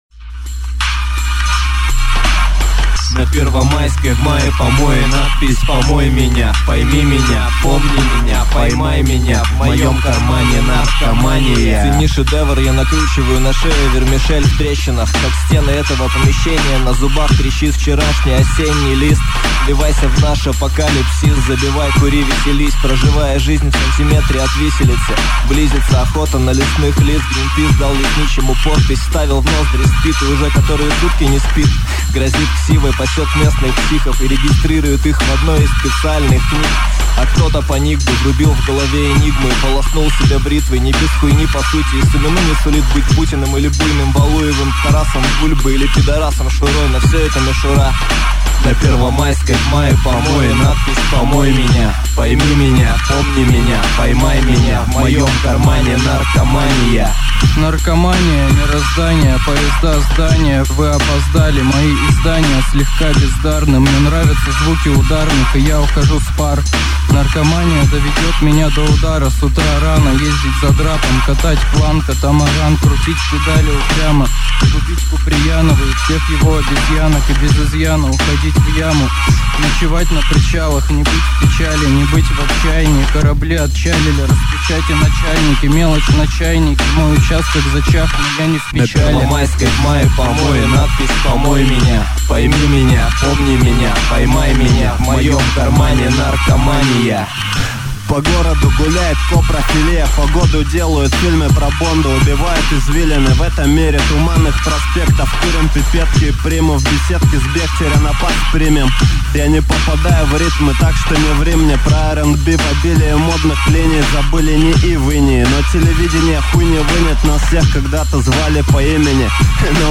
Рэп (46679)